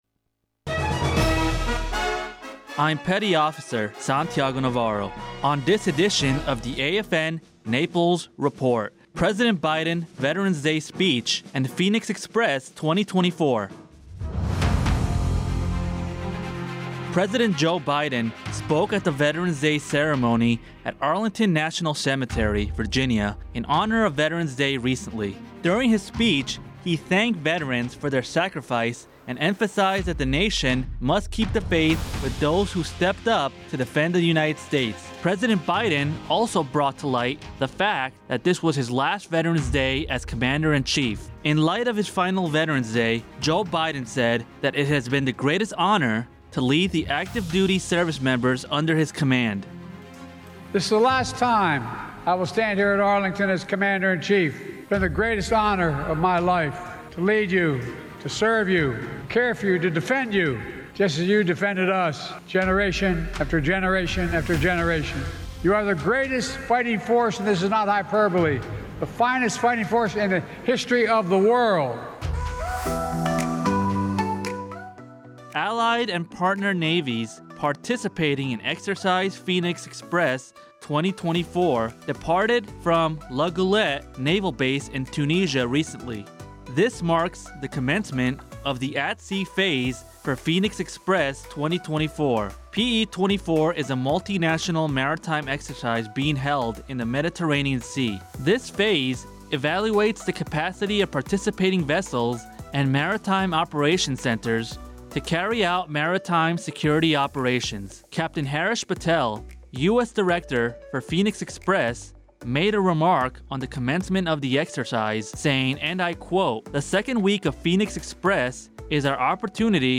AFN Naples Radio News- President Biden Veteran's Day Speech And Phoenix Express 2024